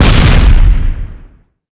r_explode.wav